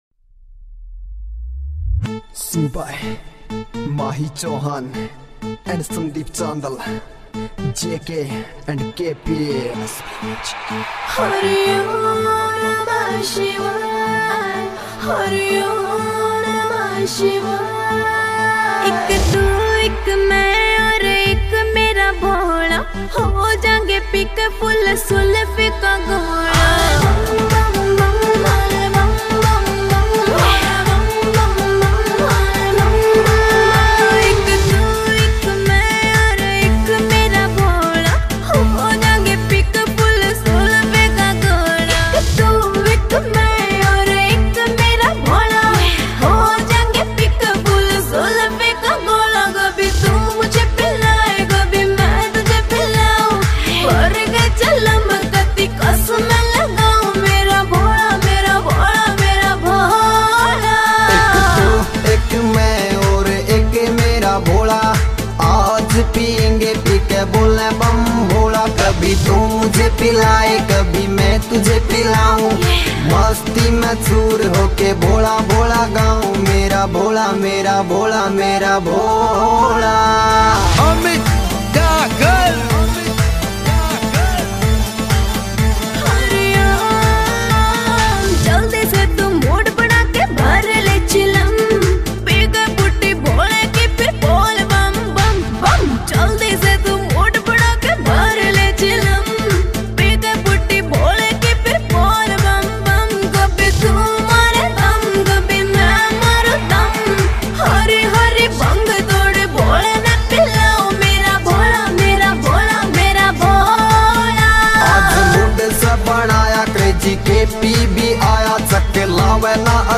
» Bhakti Songs
» Haryanvi Songs